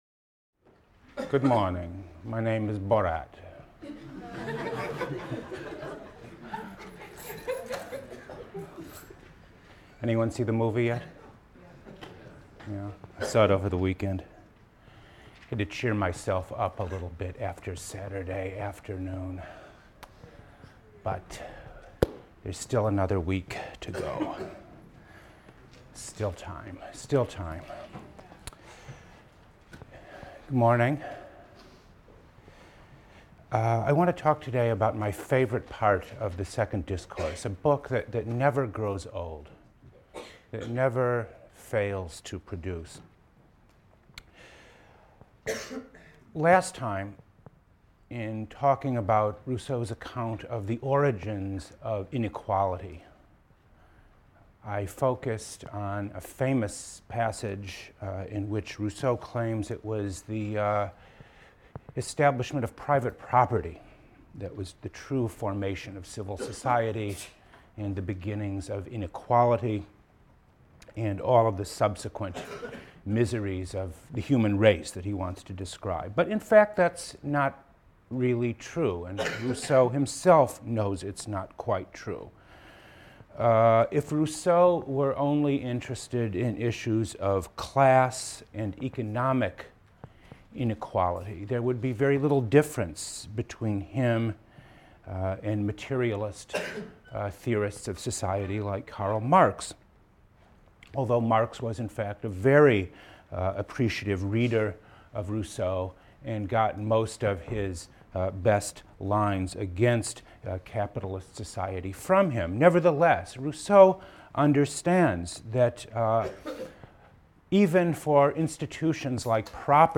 PLSC 114 - Lecture 19 - Democracy and Participation: Rousseau, Discourse on Inequality (Part II) | Open Yale Courses